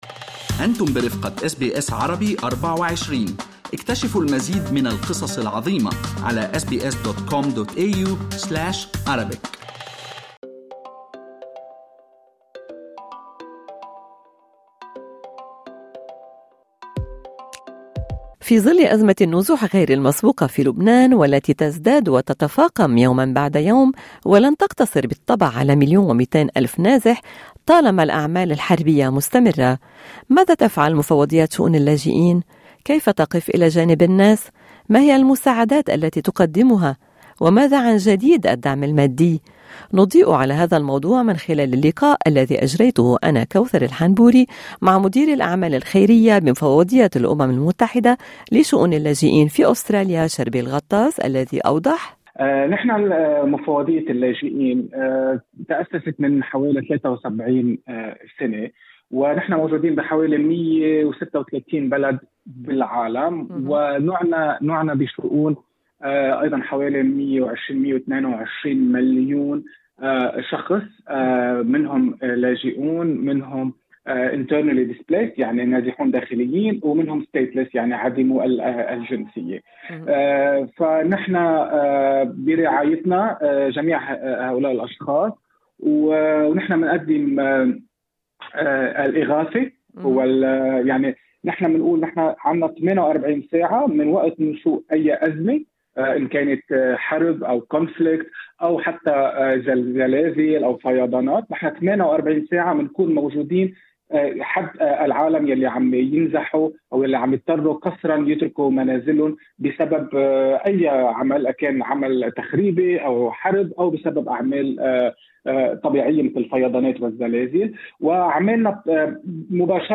نضيء على هذا الموضوع وعمل المفوضية وجهود مكتب أستراليا في حشد الدعم من خلال هذا اللقاء